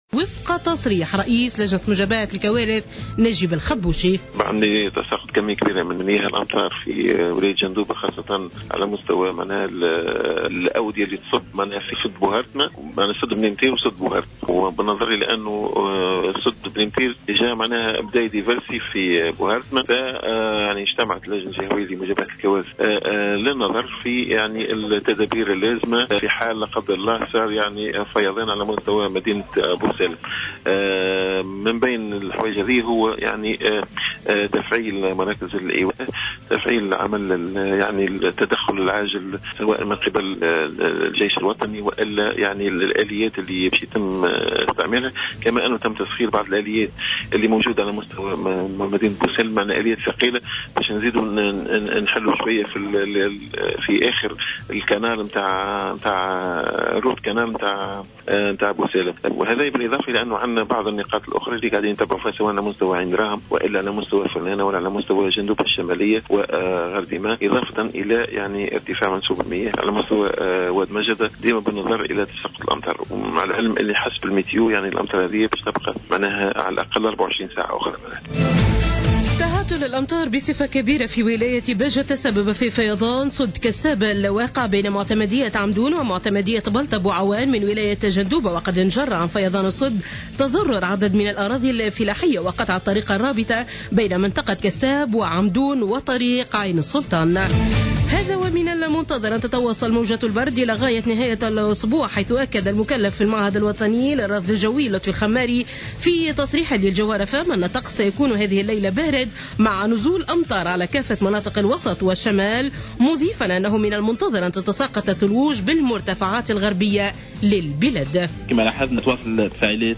Journal Info 00h00 du 26-02-15